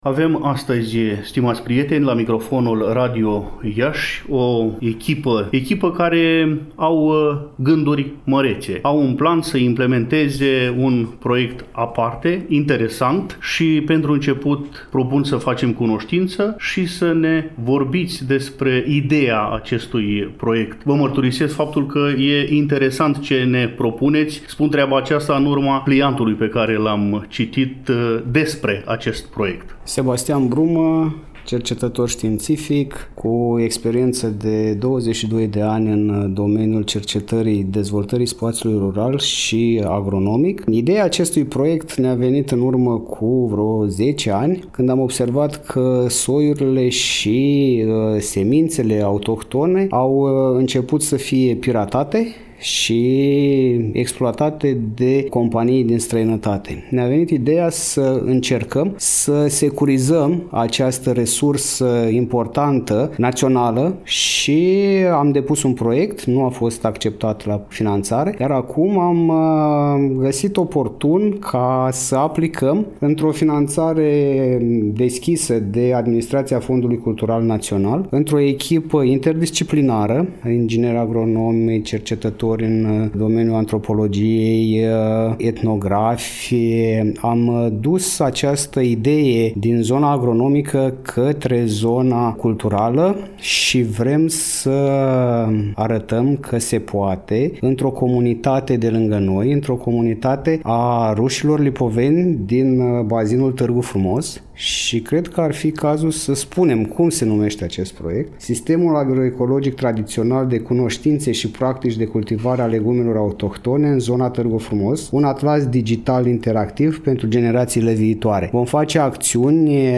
Pentru a afla amănunte concrete atât despre obiectivele proiectului, cât și despre principalele activități din cadrul acestuia, am invitat la dialog pe membrii echipei care se ocupă de implementare.